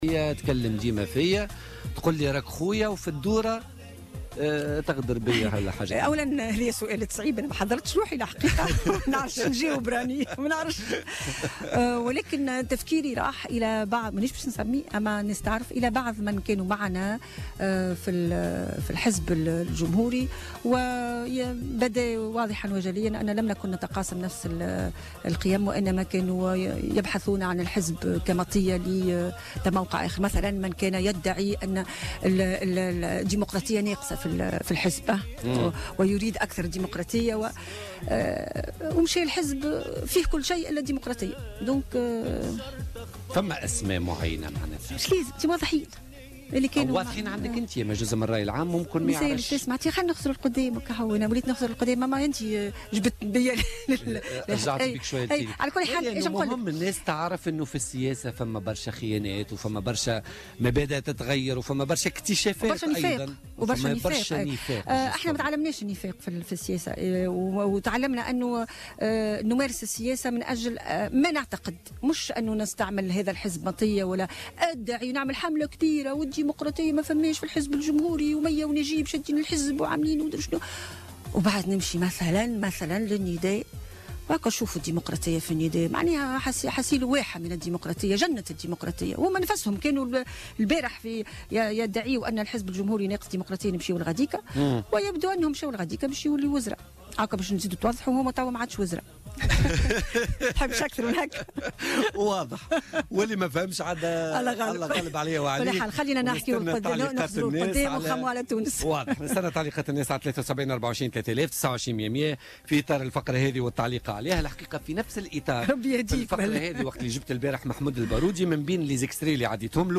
لمحت القيادية في الحزب الجمهوري مية الجريبي ضيفة بوليتيكا اليوم 12 أفريل 2017 للمنتقلين حديثا لحركة نداء تونس واصفة ما حدث بأكبر علامات الانحطاط السياسي.